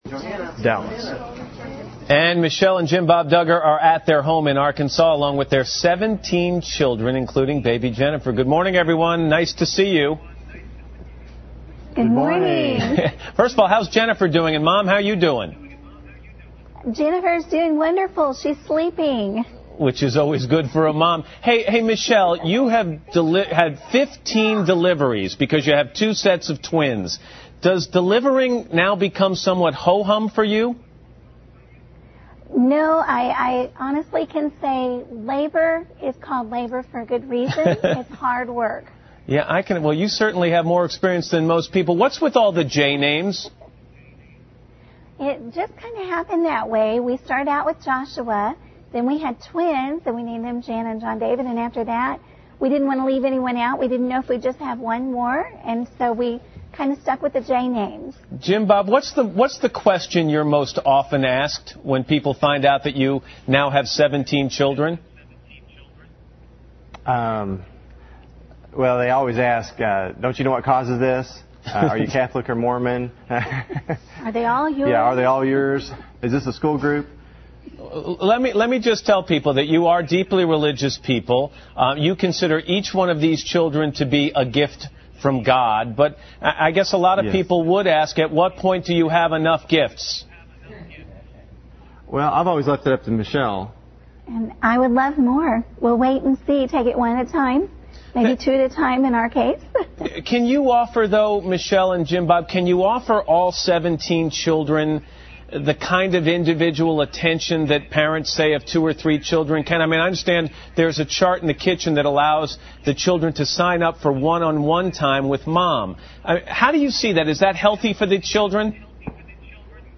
访谈录 Interview 2007-08-13&08-15, 大家庭：一家19口的生活 听力文件下载—在线英语听力室